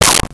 Whip 01
Whip 01.wav